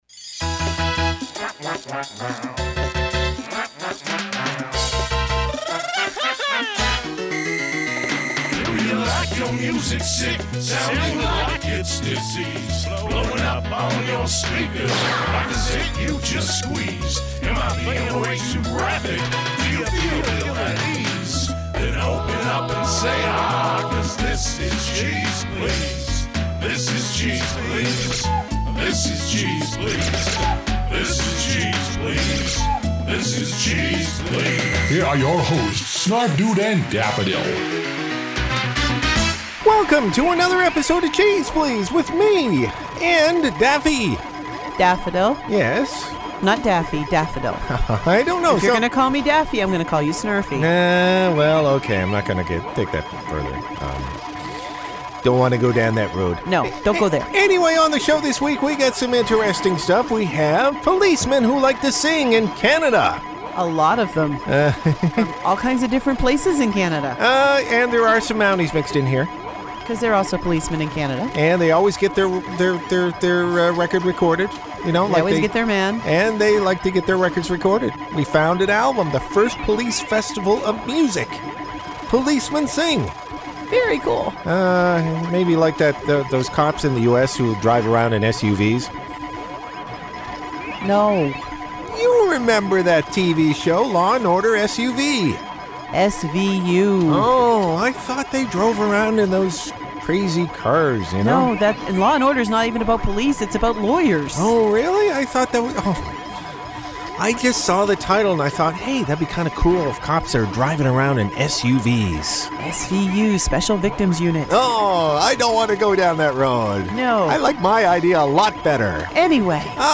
Canuck Cops Croon on a fabulous 2 record set....